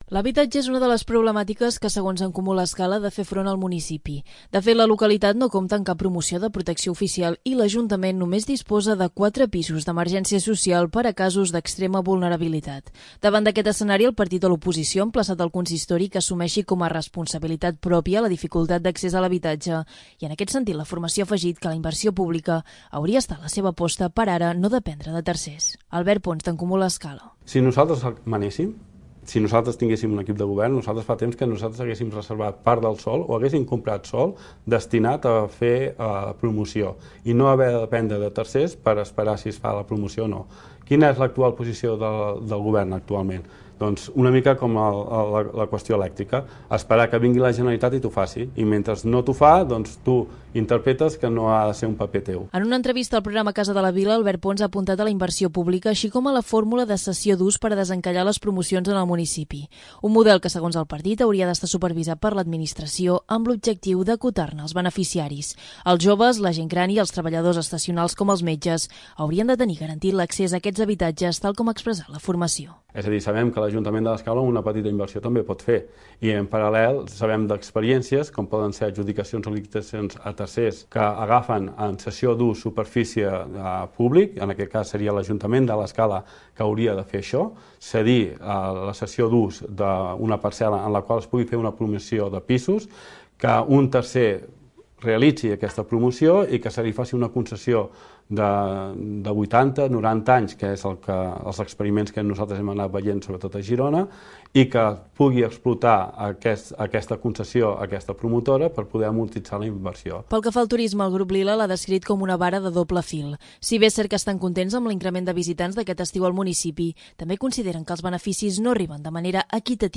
2. L'Informatiu